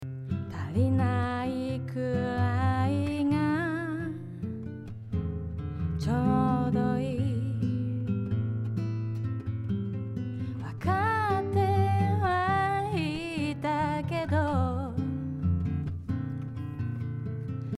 そこで試験的にマスターに挿したEQでこの周辺の周波数を少し下げてみました。
この辺りを少し抑えることですっきりと明瞭に聴こえるようになりましたね。